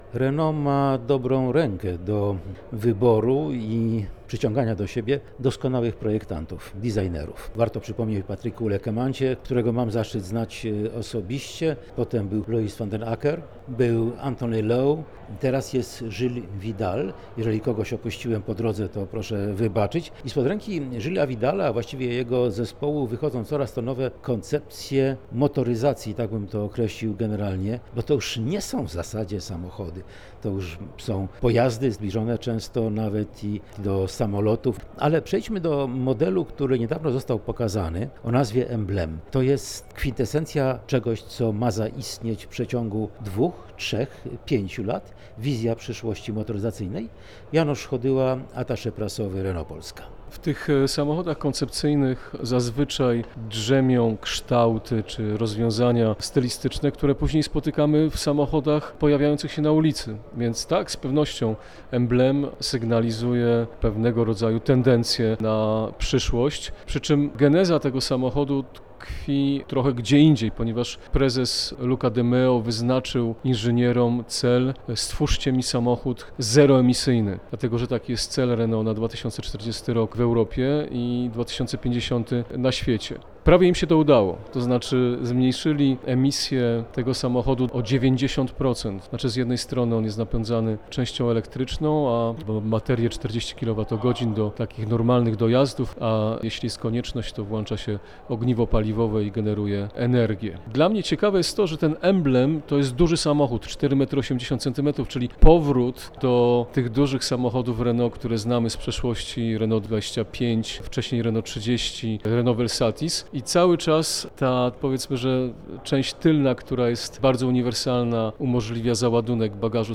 Przy takiej okazji obecni producenci aut pokazują też, jak dalece projektanci nowych modeli czerpią inspirację z historii marki. Rozmowa